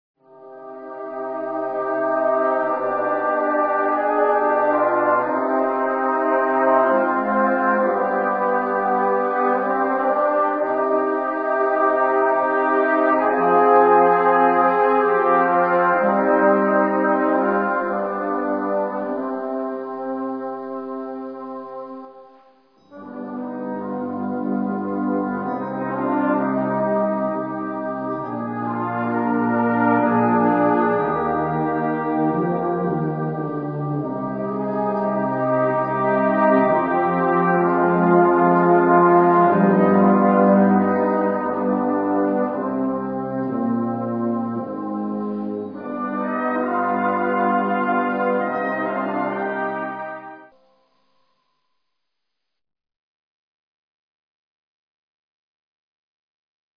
• Une suite facile mais fascinante en trois parties.